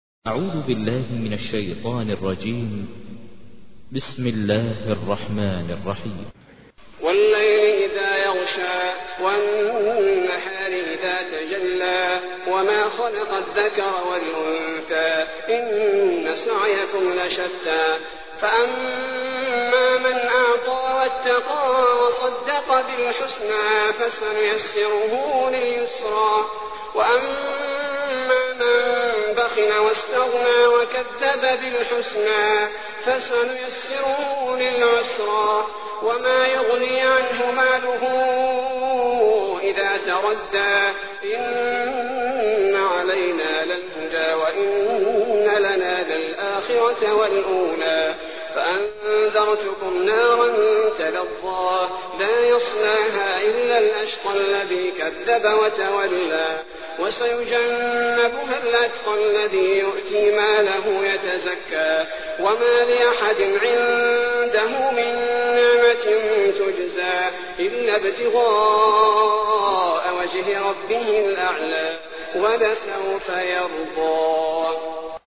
تحميل : 92. سورة الليل / القارئ ماهر المعيقلي / القرآن الكريم / موقع يا حسين